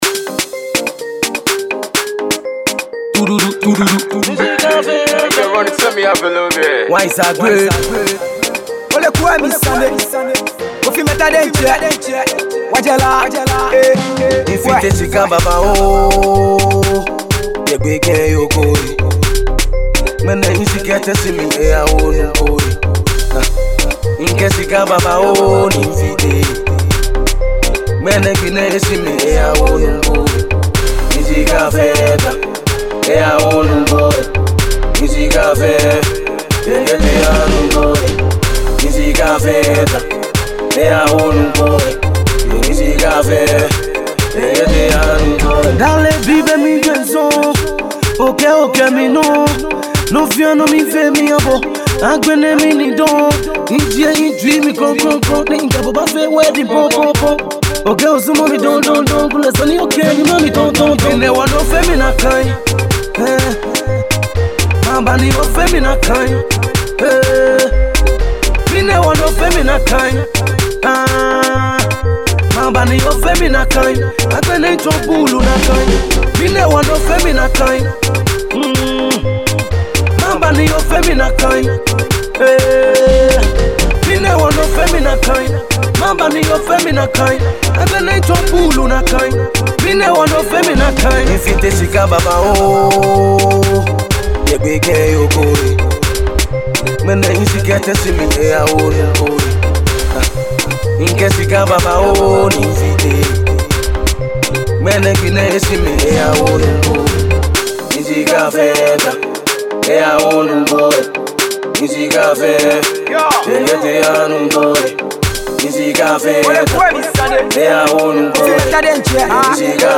This is a dope afrobeat song.